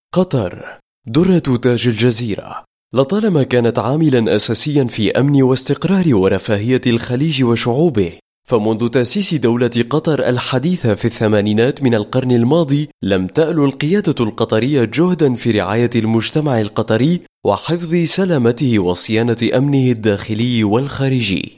I am a professional arabic voice over talent.
Sprechprobe: Industrie (Muttersprache):